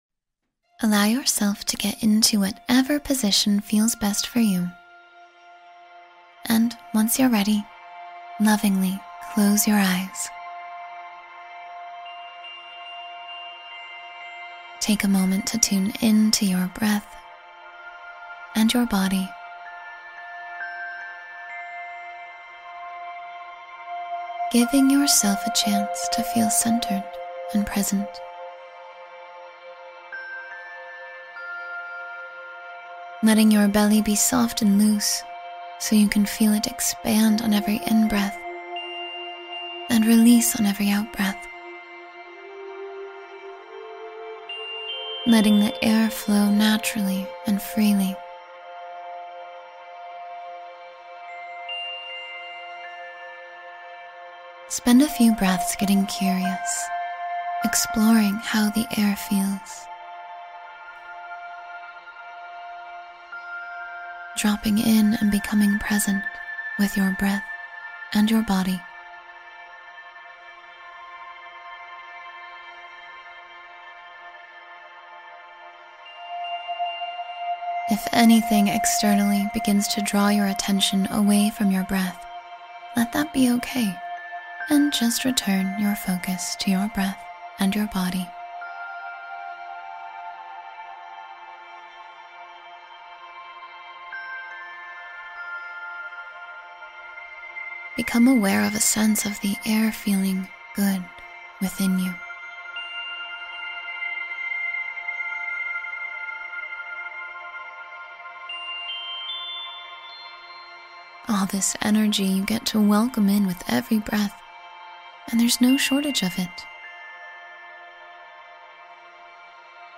Guided mindfulness exercises